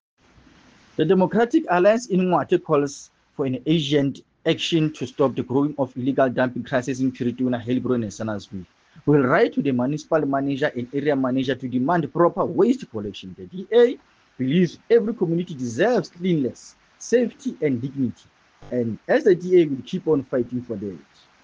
Sesotho soundbites by Cllr Joseph Mbele.